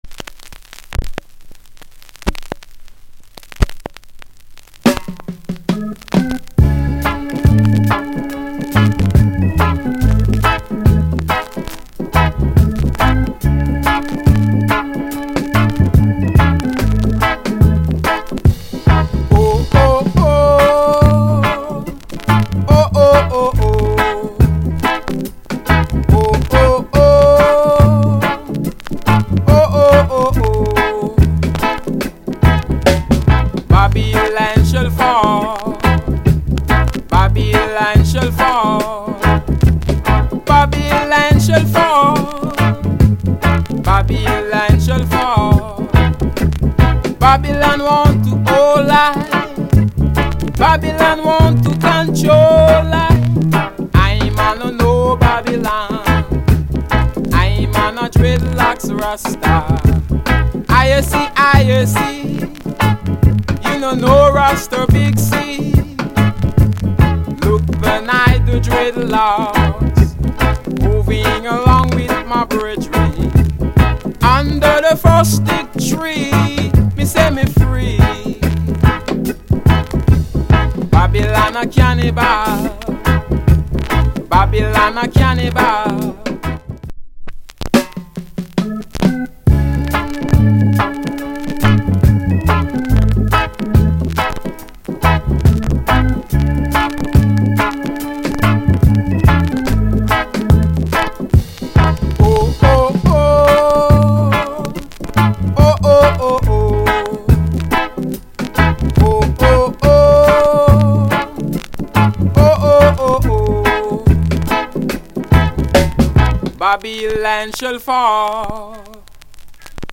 * Very Rare Killer Roots **エッジが欠けておりさらにそこから1c目ほどのクラック二本。